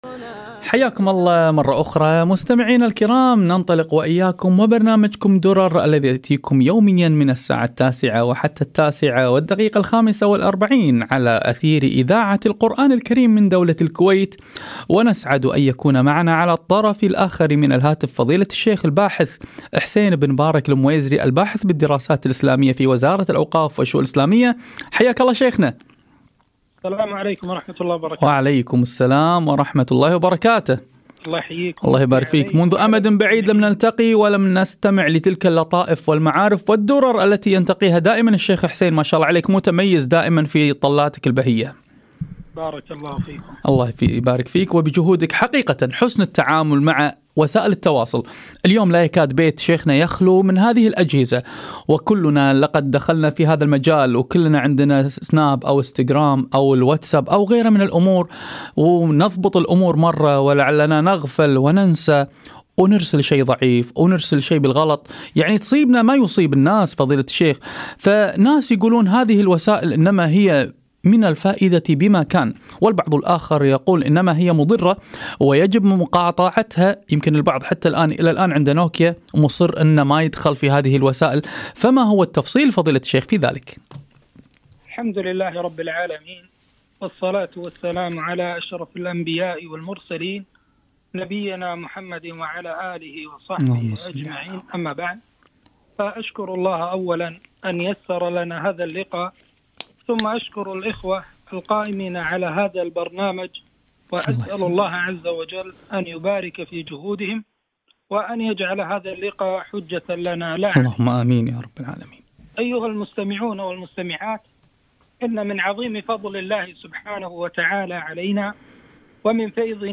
حسن التعامل مع وسائل التواصل - لقاء إذاعة القرآن الكريم